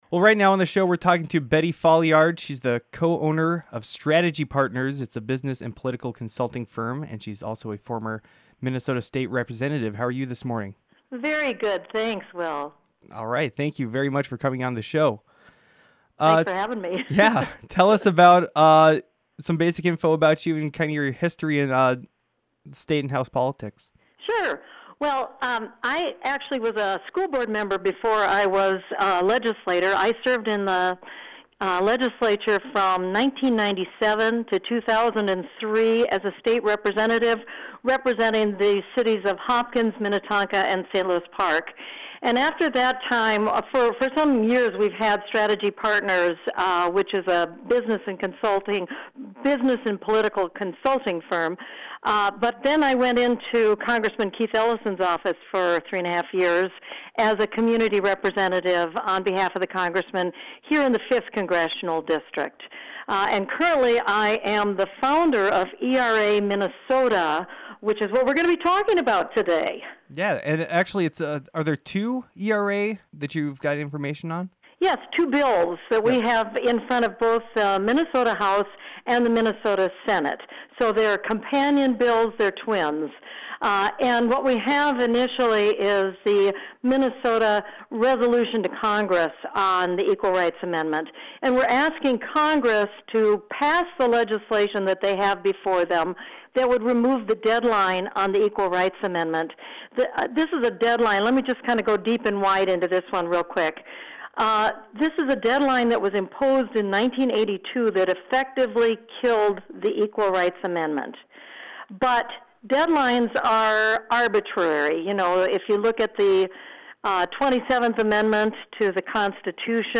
Attachment Size BettyFolliardWEBFinal_01.mp3 31.45 MB Betty Folliard is a former state representative and the founder of the organization ERA Minnesota.